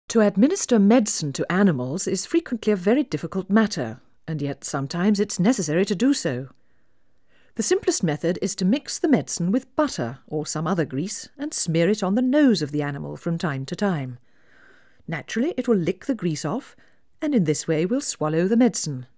Female Speech Mono